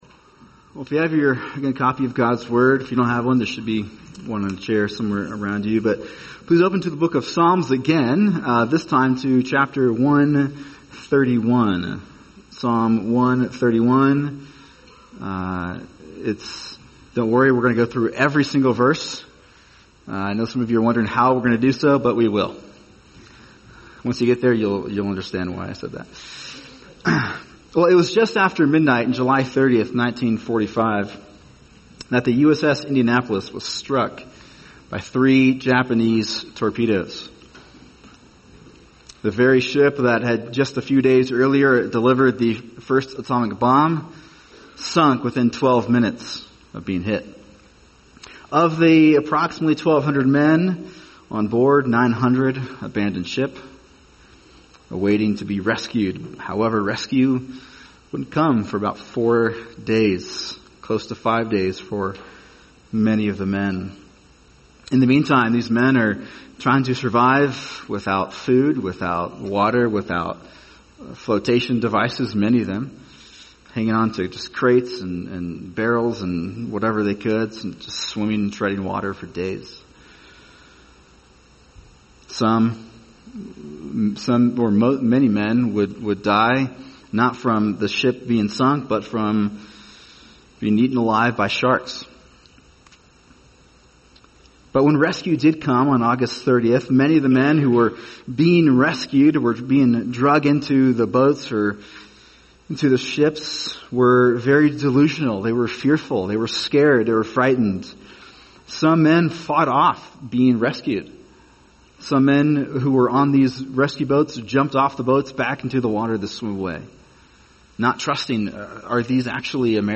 [sermon] Psalm 131 – A Simple Trust | Cornerstone Church - Jackson Hole